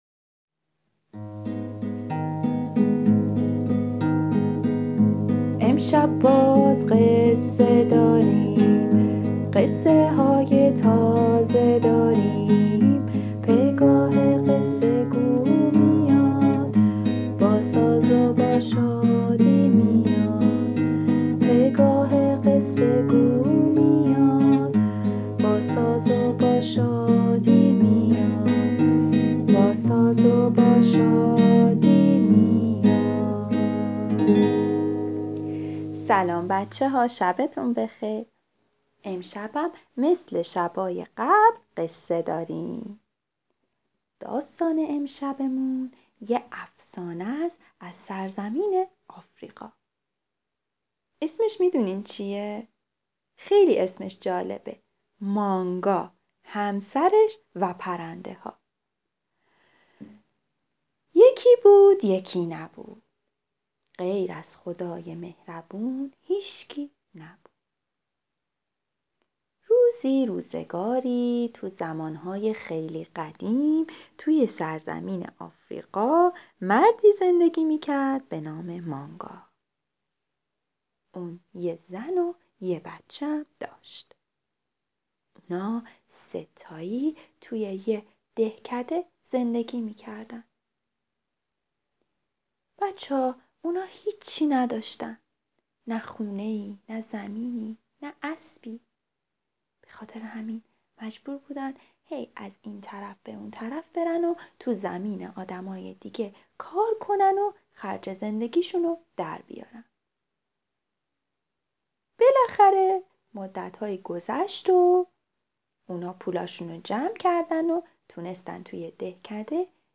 قصه صوتی کودکان دیدگاه شما 1,574 بازدید